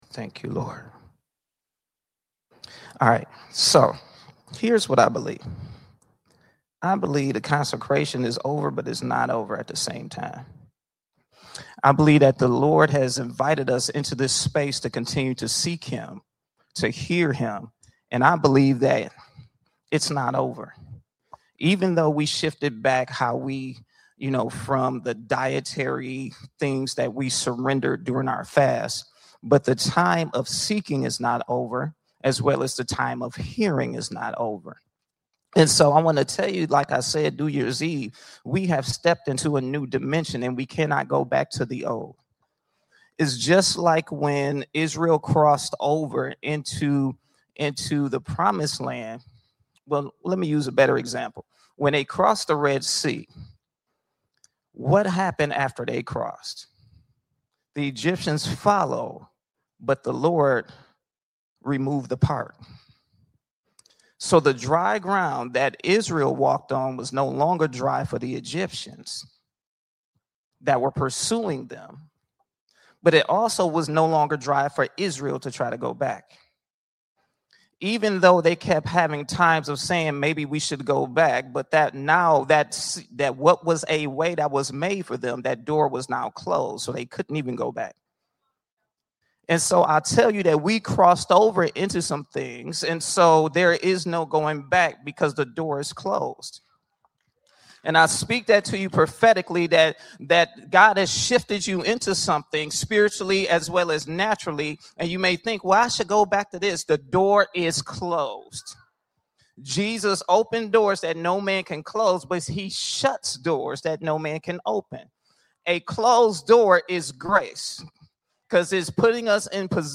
Category: Teachings